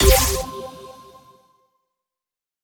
Base game sfx done
Futuristic Reward.wav